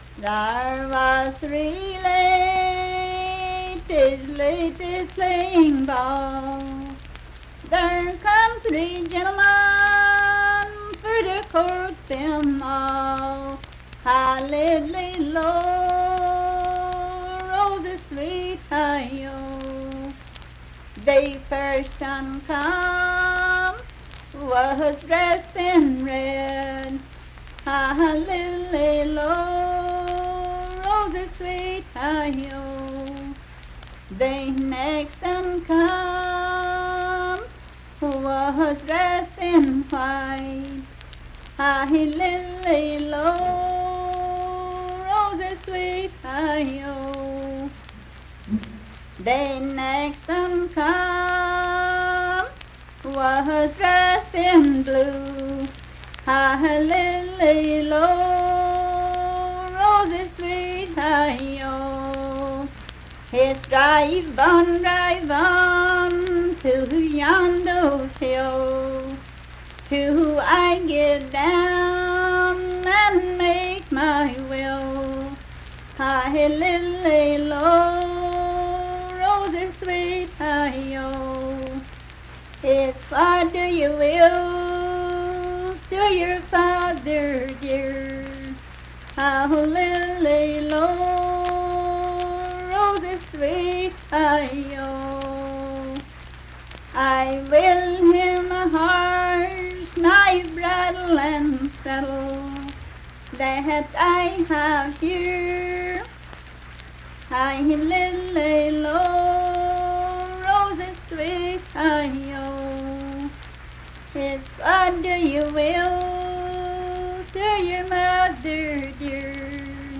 Unaccompanied vocal music
Performed in Big Creek, Logan County, WV.
Voice (sung)